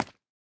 Minecraft Version Minecraft Version snapshot Latest Release | Latest Snapshot snapshot / assets / minecraft / sounds / block / candle / break1.ogg Compare With Compare With Latest Release | Latest Snapshot
break1.ogg